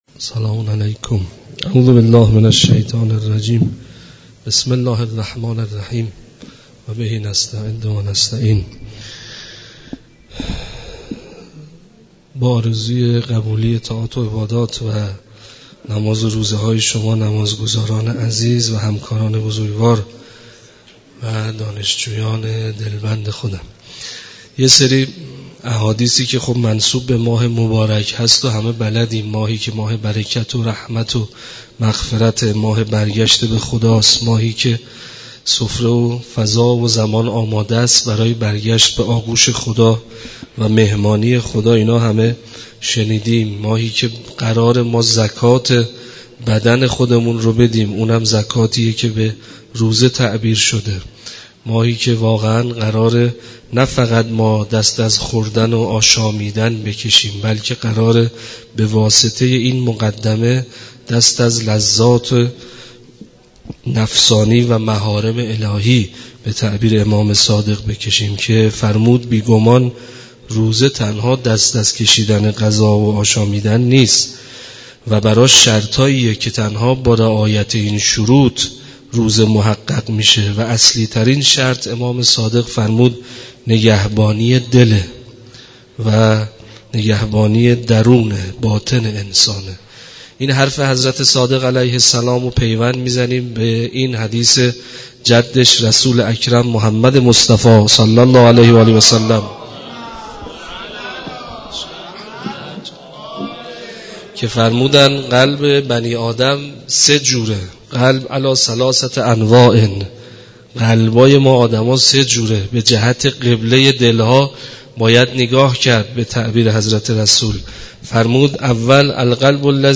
32-لینک سخنرانی در مسجد دانشگاه (چهارشنبه 7-8-1404)